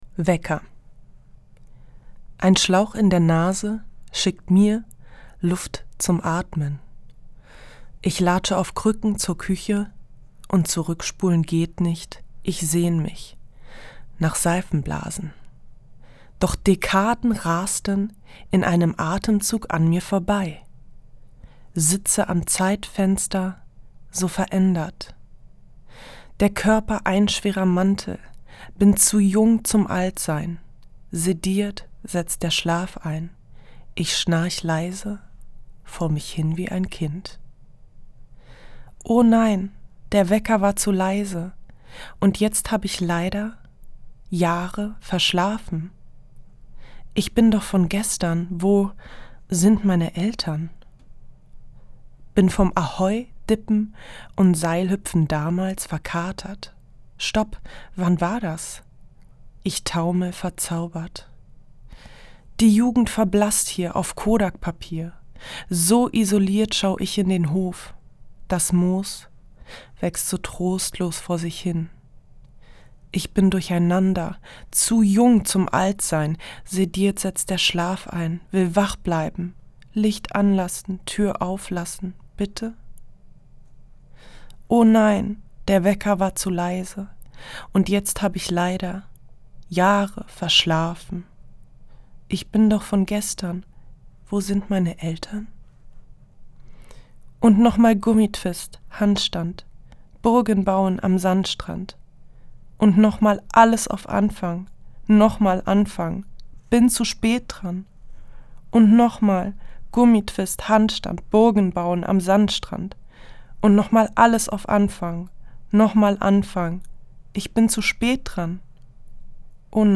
Das radio3-Gedicht der Woche: Dichter von heute lesen radiophone Lyrik.
Gelesen von Balbina.